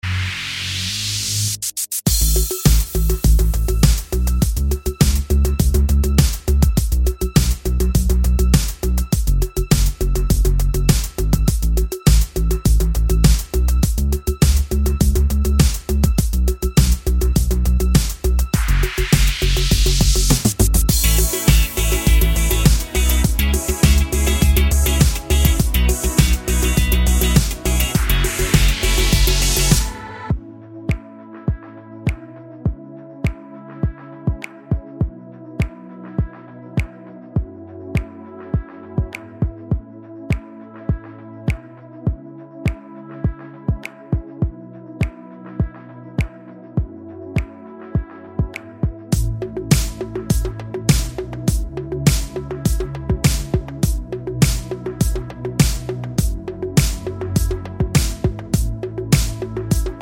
No Backing Vocals Or Vocoder Pop (2010s) 3:53 Buy £1.50